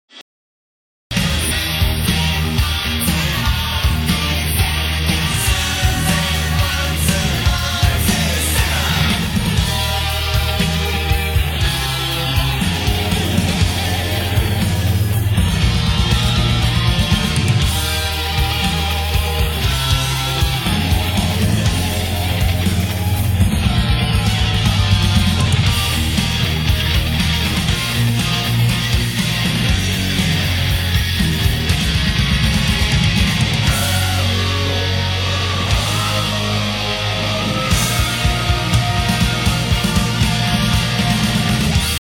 真ん中のソロが消えるように設定してみると・・・
おー、消えてる！！
まぁ、同じ周波数帯域にある、スネアとかも一緒に消えちゃってますが。
↓カラオケ適用後